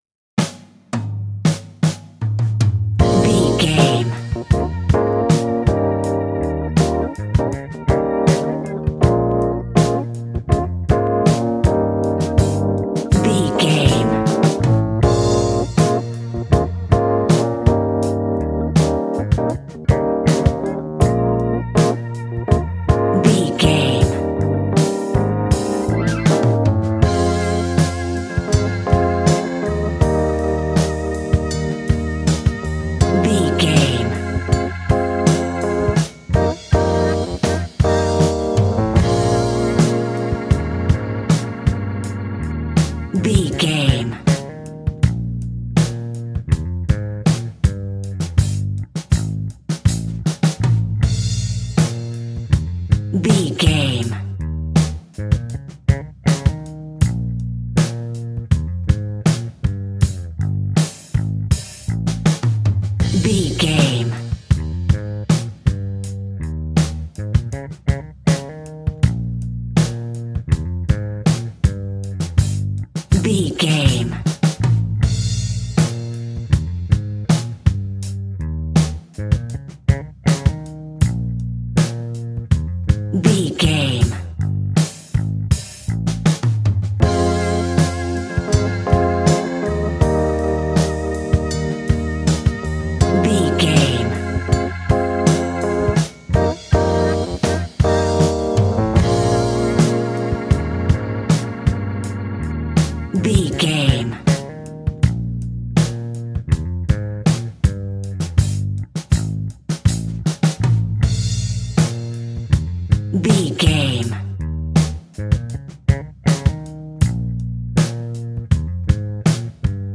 Aeolian/Minor
B♭
funky
uplifting
organ
saxophone